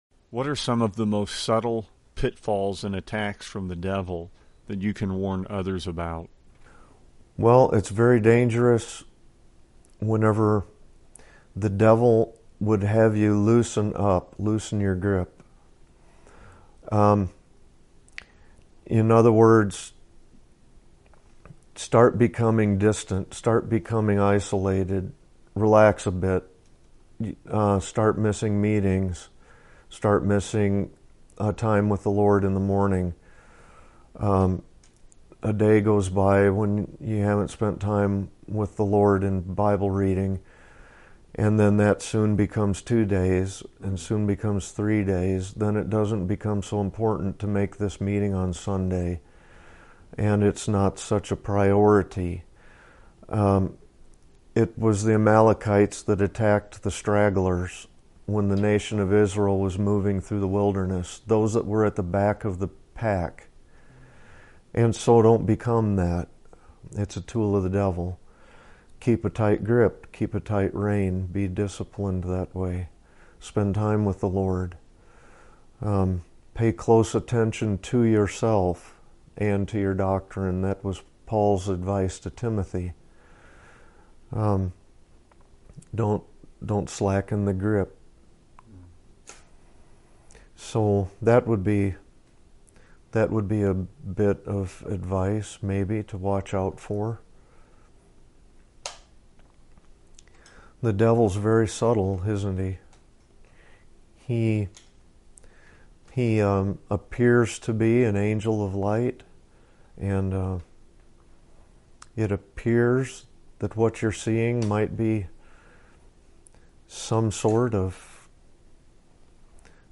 Interview | 2:48 | The enemy of our souls wants us to become distant from the Lord; to loosen our grip when it comes to our intimate relationship with Christ and to not make that the prime priority.